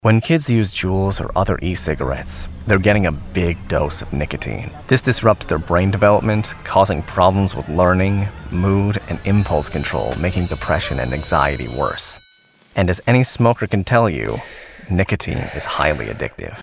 Naturelle, Amicale, Chaude
Vidéo explicative